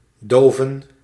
Ääntäminen
IPA: [sɑ̃.ti.mɑ̃]